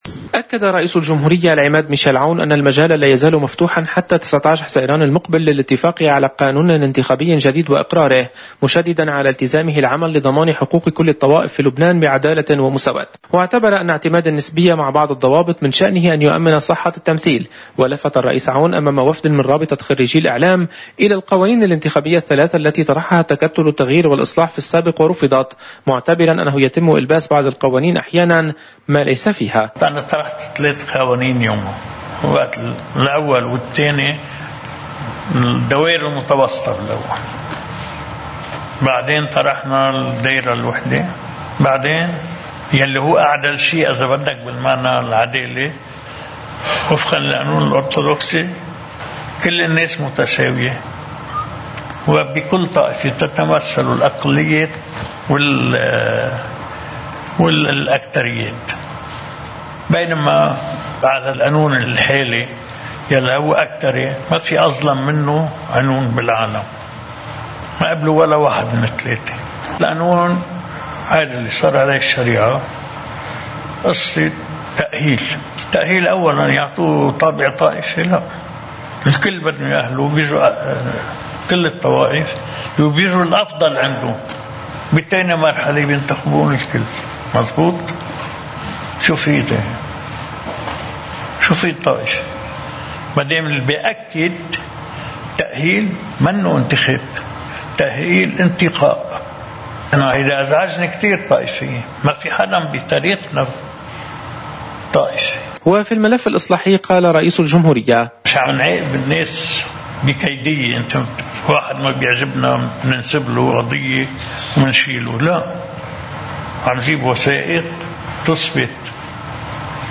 مقتطف من حديث الرئيس الجمهورية العماد ميشال عون امام وفد من رابطة خريجي الإعلام: